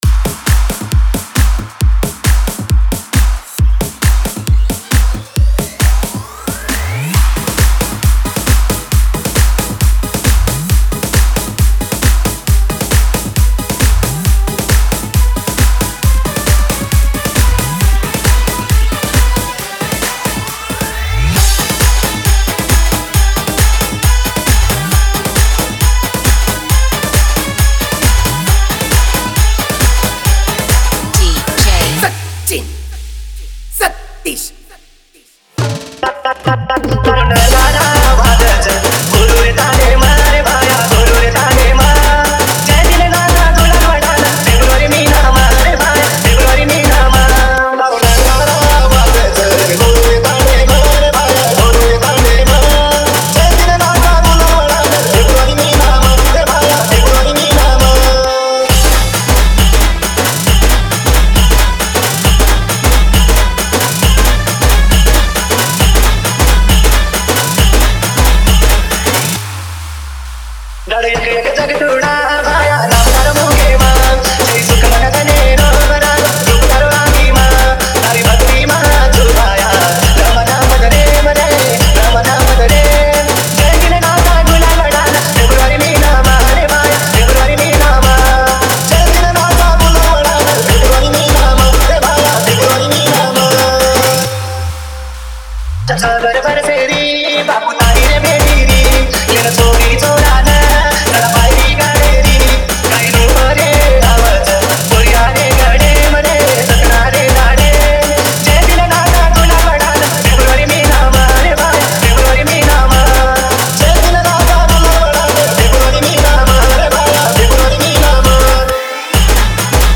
Banjara Dj Tracks